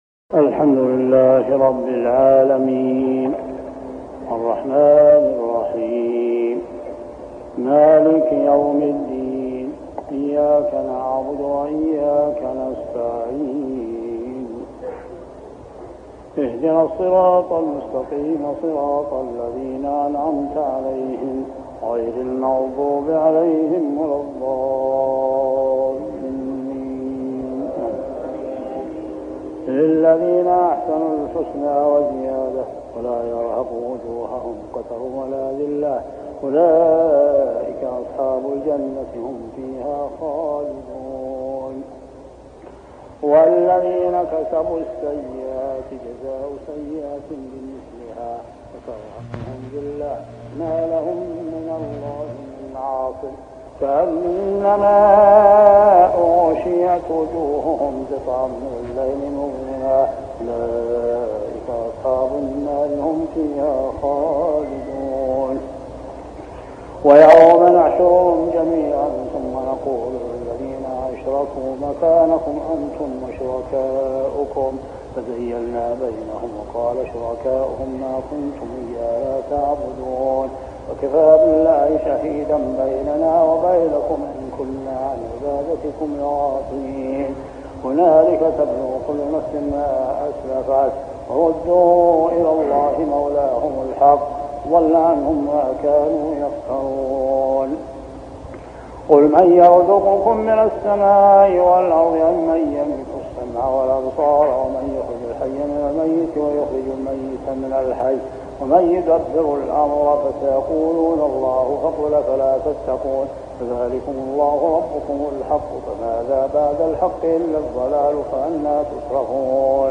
صلاة التراويح عام 1403هـ سورة يونس 26-62 | Tarawih prayer Surah Yunus > تراويح الحرم المكي عام 1403 🕋 > التراويح - تلاوات الحرمين